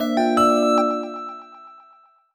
jingle_chime_13_positive.wav